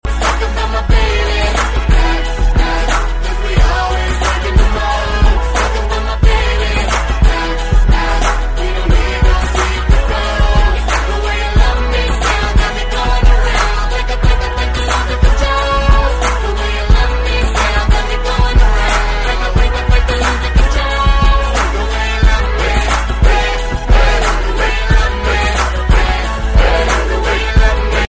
RnB & Garage